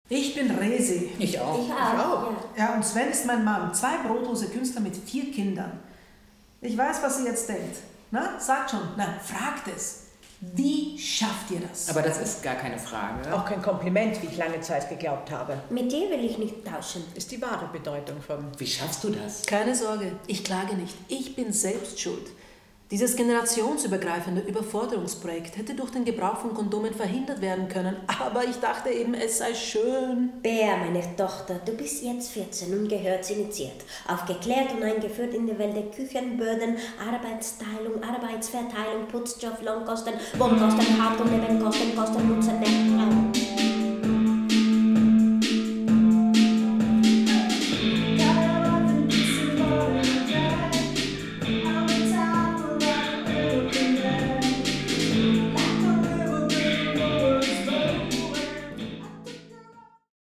Alle sind Resi, sprechen aber auch andere Figuren. Mit Songs von Bruce Springsteen und anderen Hits aus den 80ern, die in den Text verwoben sind, schreien sie ihre Wut heraus.